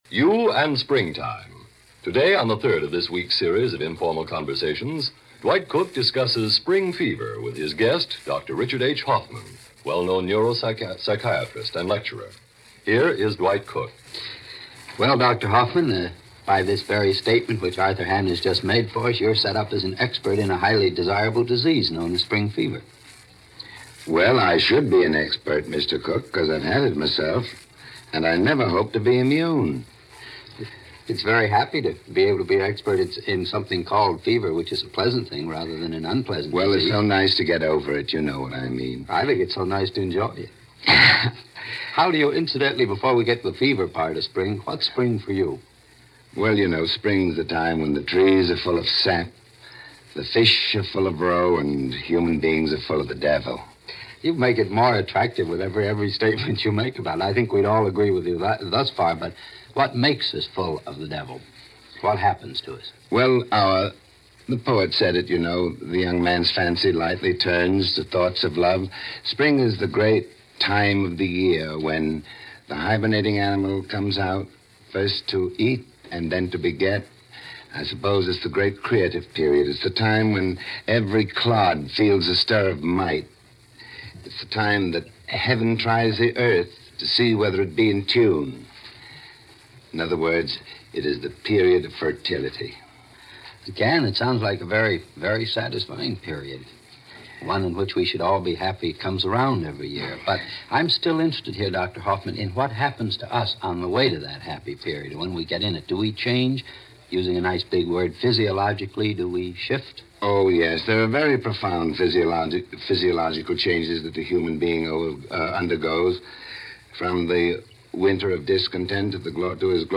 You And Springtime - April 5, 1950 - Past Daily Sunday Gallimaufry - broadcast by CBS Radio - Past Daily Sunday Gallimaufry